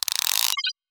gearup.wav